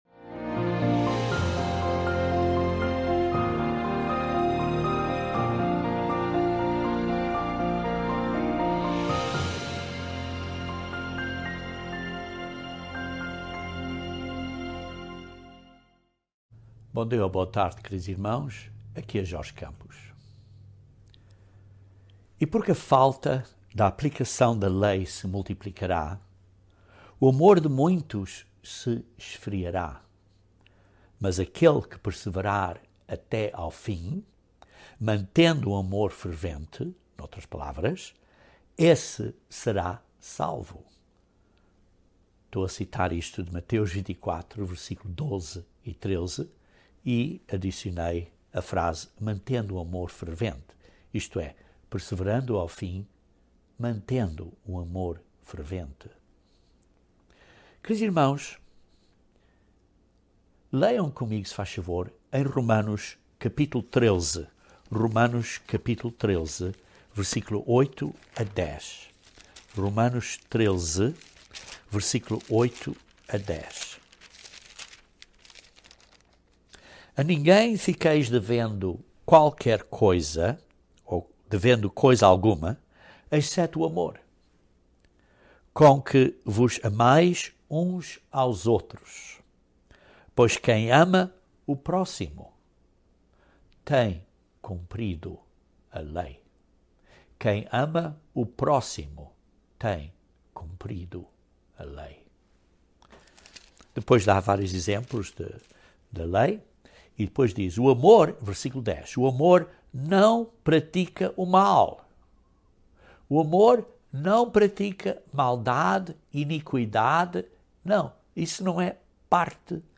Este sermão aborda um ponto importante do amor que se esfriará, e dá alguns princípios para nos ajudar a crescer em amor para com o próximo.